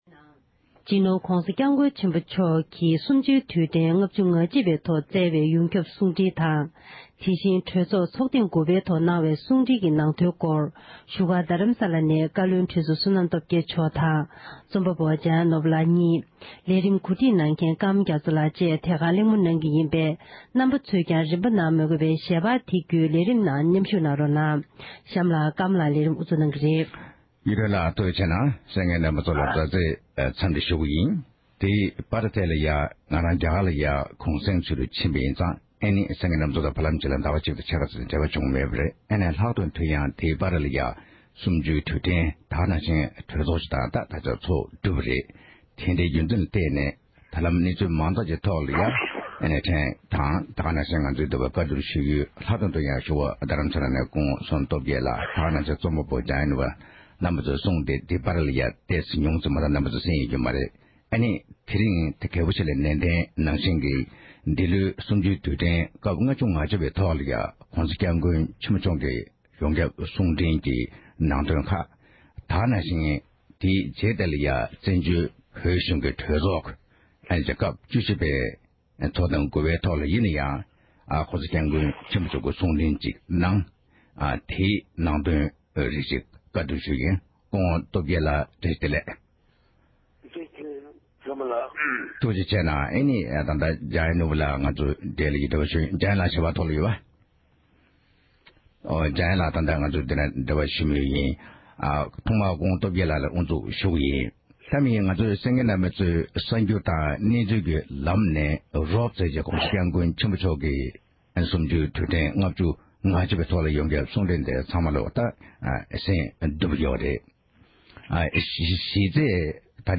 གླེང་མོལ་གནང་བ་ཞིག་གསན་རོགས༎